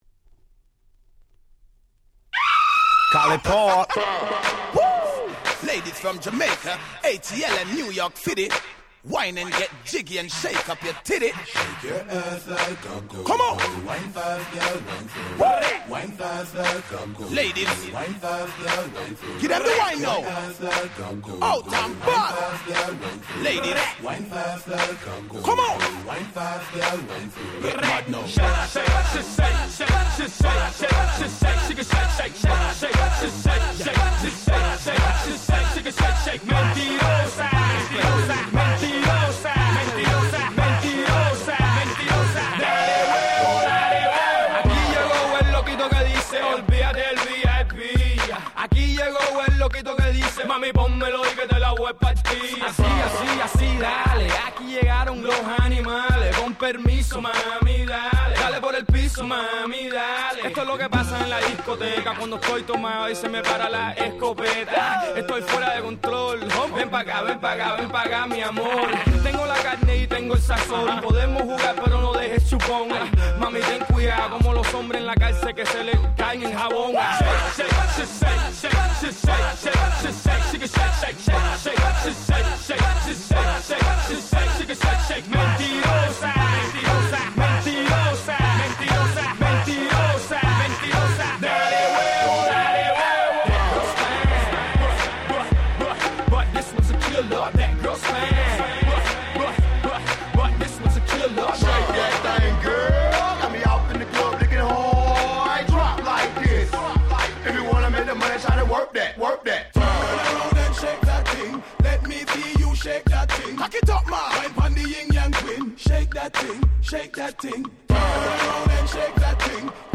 05' Smash Hit Hip Hop !!